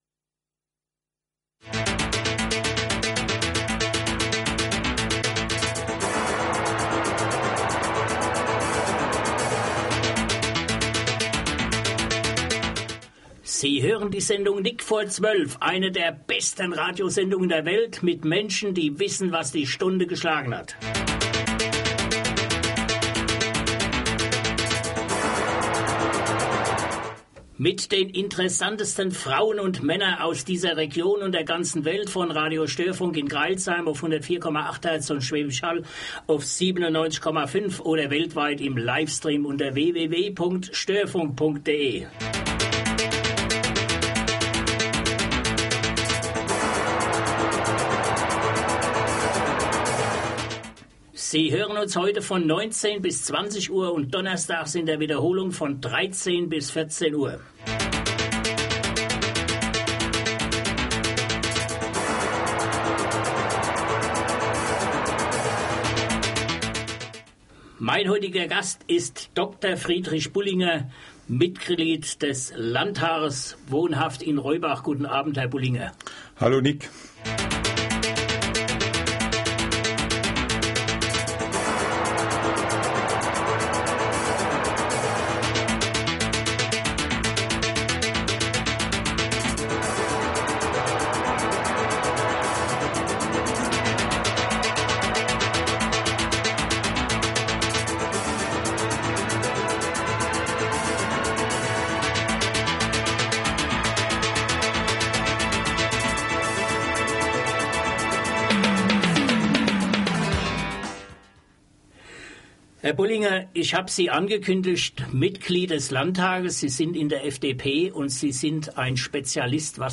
hören Sie rein was er Ihnen spannendes am 11. Dez. zur gewohnten Sendezeit von 19-20 Uhr in der Sendung